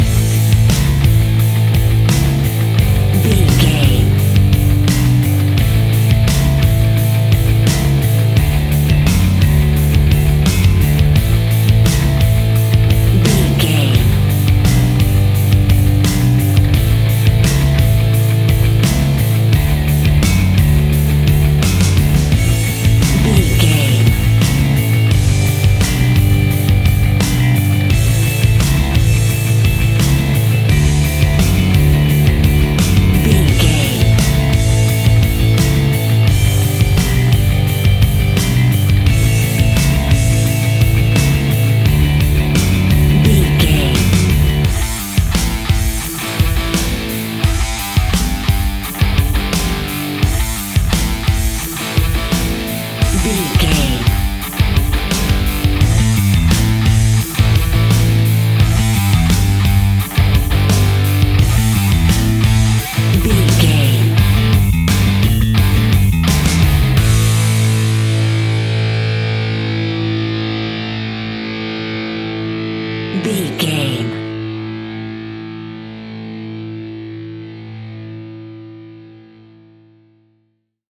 Epic / Action
Fast paced
Aeolian/Minor
hard rock
blues rock
distortion
instrumentals
Rock Bass
heavy drums
distorted guitars
hammond organ